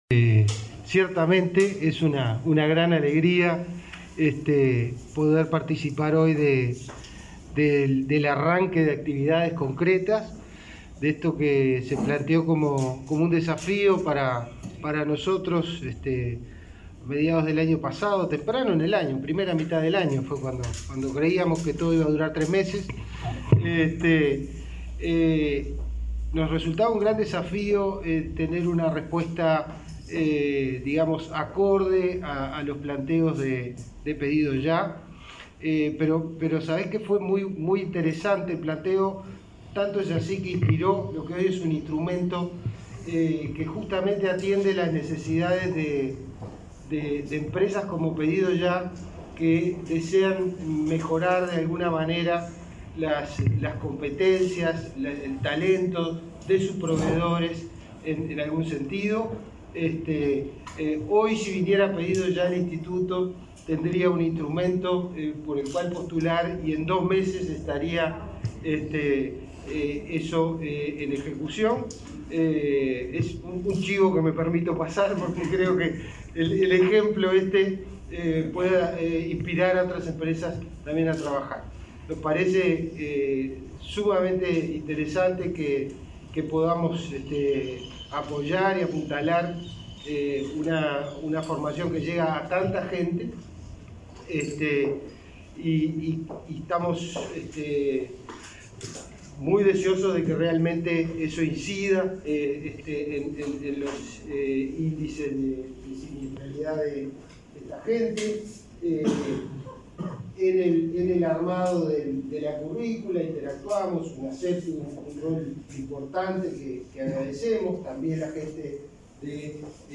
Declaraciones del presidente de Inefop, Pablo Darscht, y del ministro de Trabajo, Pablo Mieres